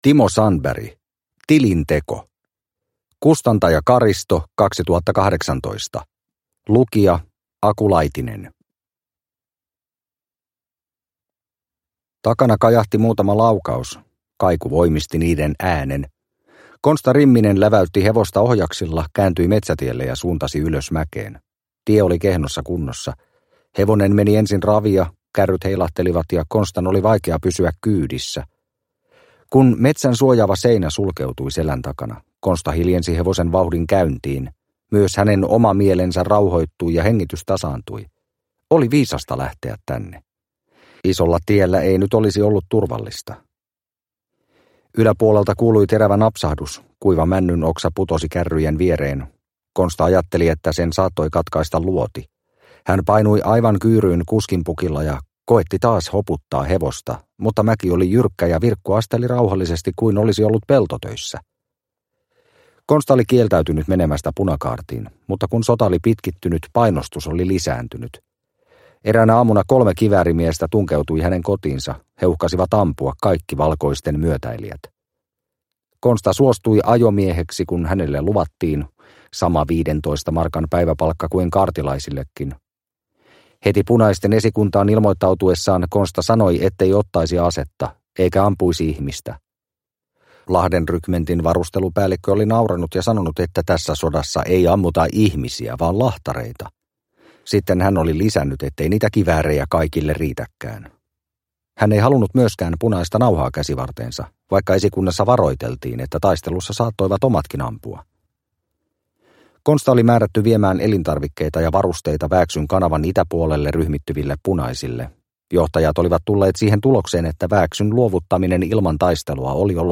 Tilinteko – Ljudbok – Laddas ner